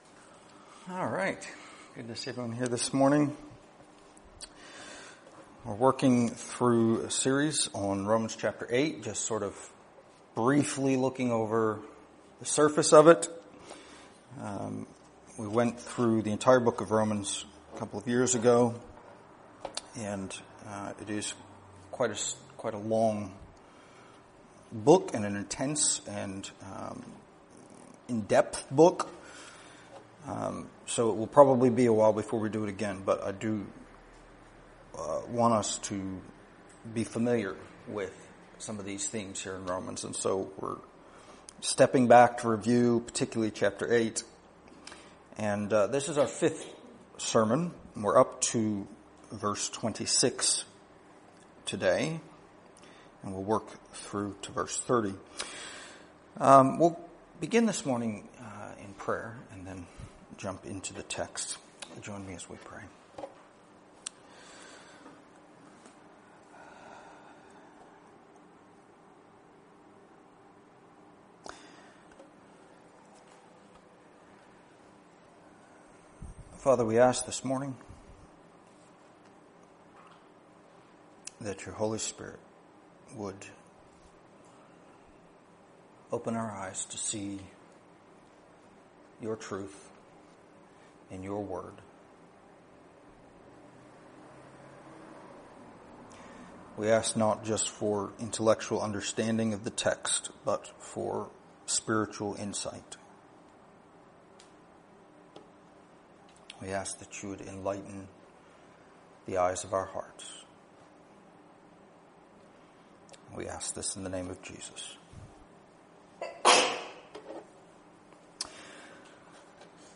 Download mp3 Previous Sermon of This Series Next Sermon of This Series Coming Soon